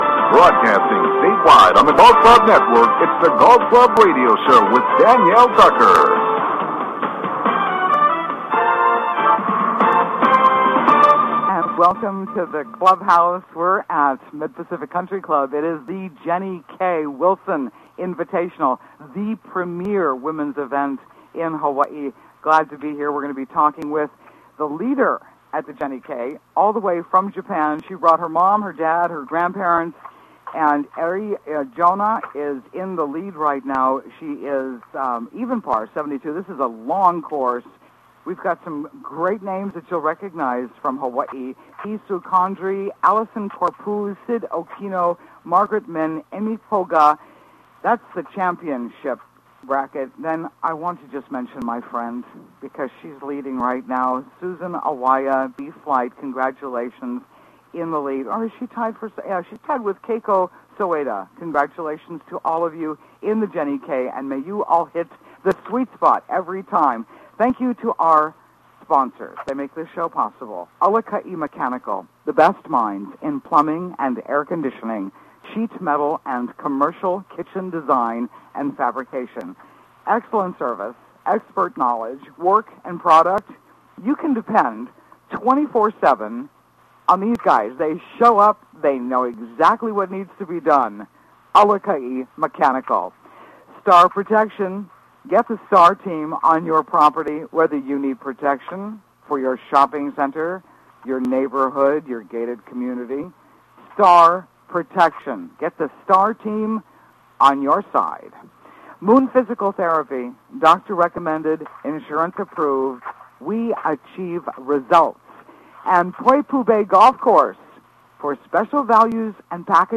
Live from The Jenny K. Wilson at The Mid Pacific Country Club, the most prestigious women�s event in Hawaii